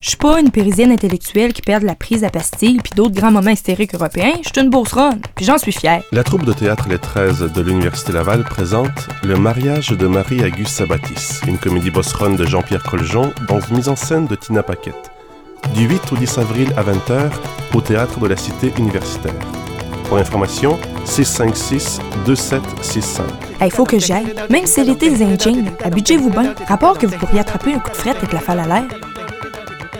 Publicité radio de Québec (mp3)